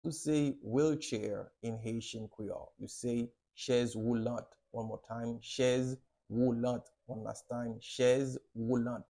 How to say "Wheelchair" in Haitian Creole - "Chèz woulant" pronunciation by a native Haitian Teacher
“Chèz woulant” Pronunciation in Haitian Creole by a native Haitian can be heard in the audio here or in the video below:
How-to-say-Wheelchair-in-Haitian-Creole-Chez-woulant-pronunciation-by-a-native-Haitian-Teacher.mp3